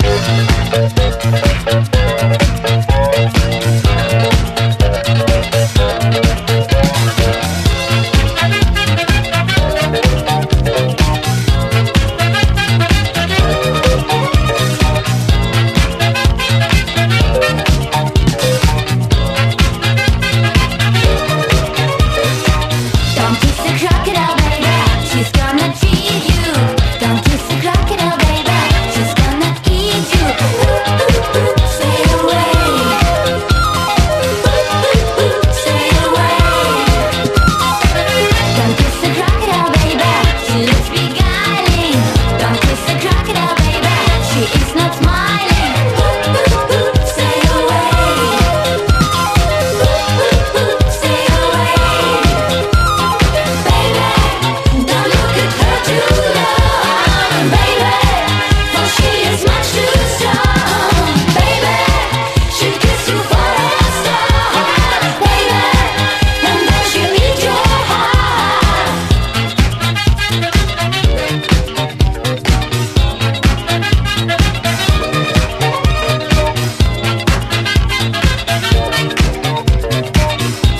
個性的なアレンジのマイナー・ラウンジ・ディスコ！